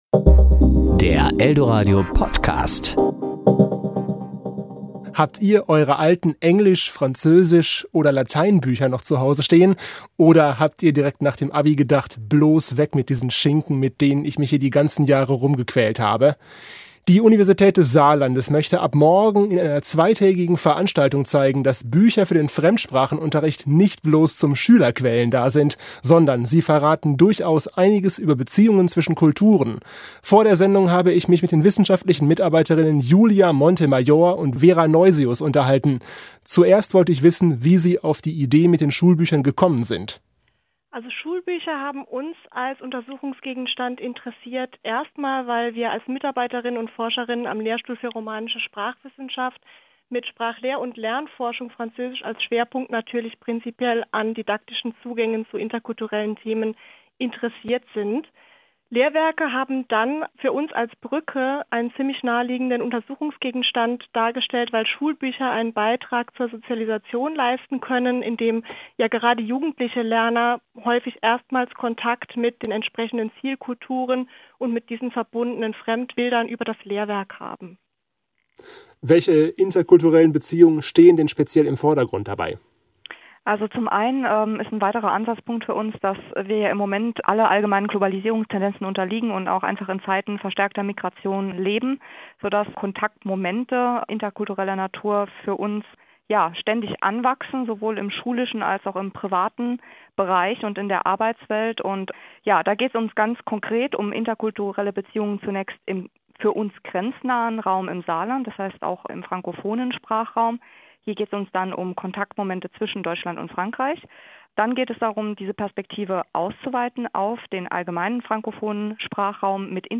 Interview  Ressort